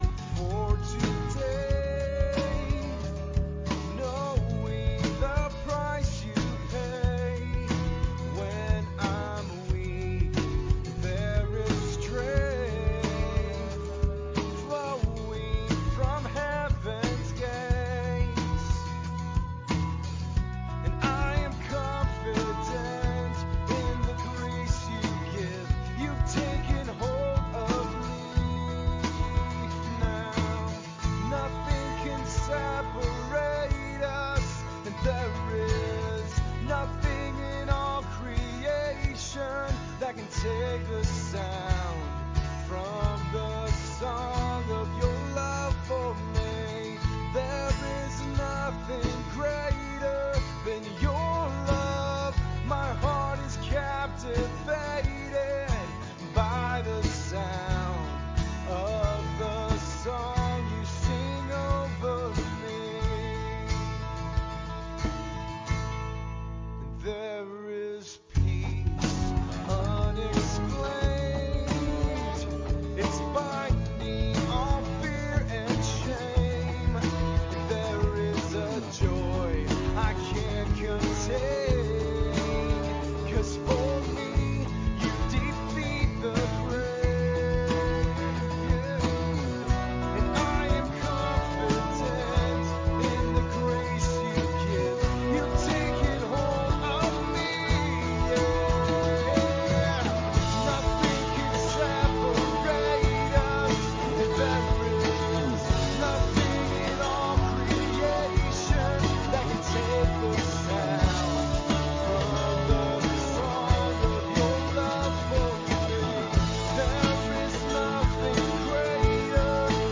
LISTEN (church service)